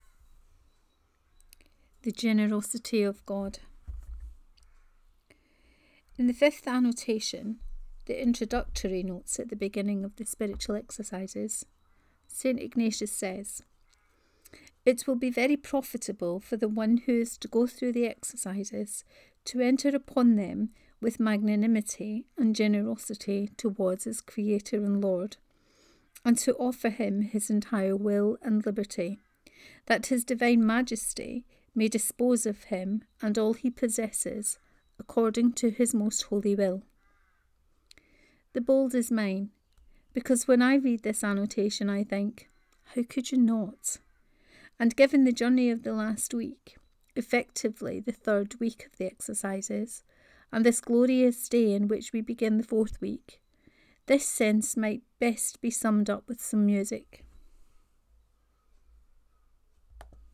The Generosity of God 1: Reading of this post.